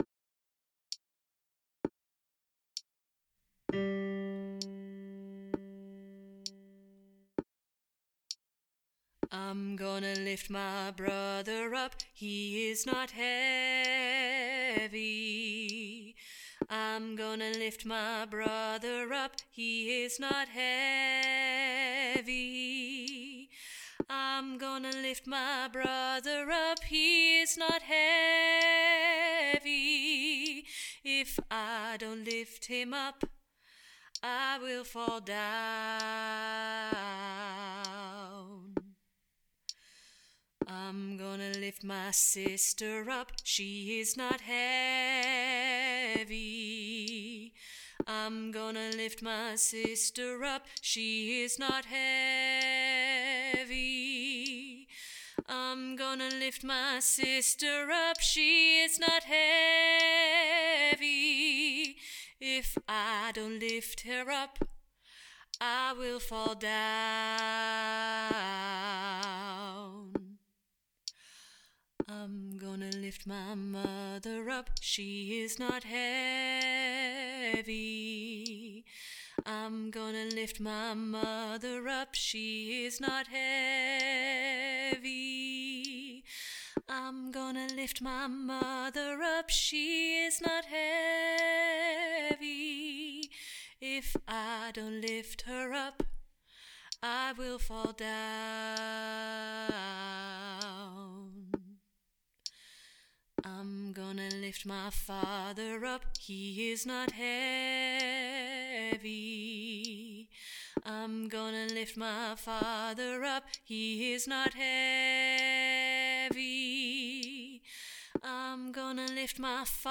IGLMBU Tenor - Three Valleys Gospel Choir
IGLMBU Tenor